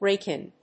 ráke ín